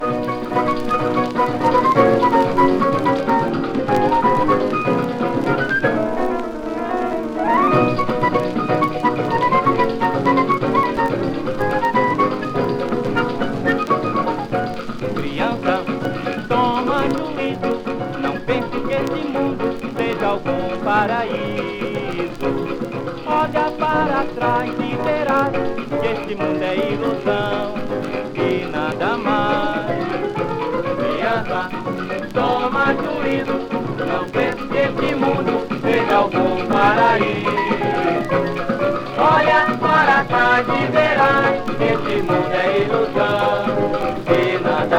ジャケスレ汚れシール貼付有　盤良好　元音源に起因するノイズ有